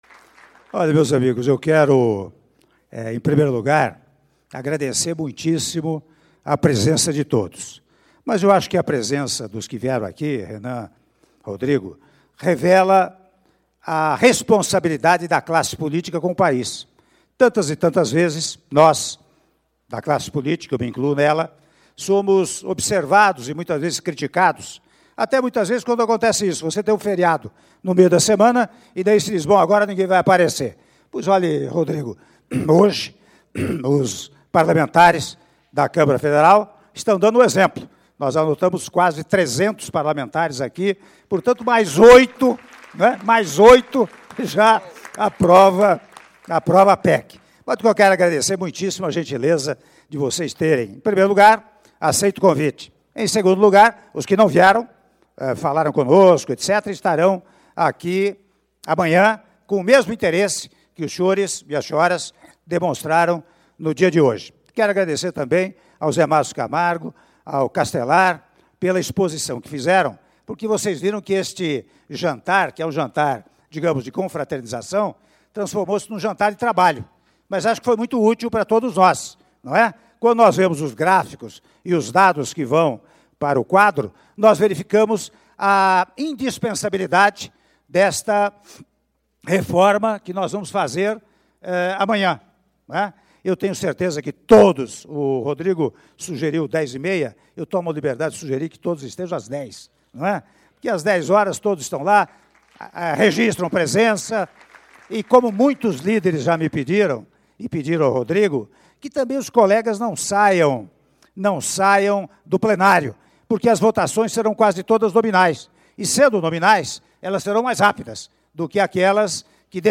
Áudio do discurso do presidente da República, Michel Temer, durante Jantar com base aliada - Brasília/DF (04min58s)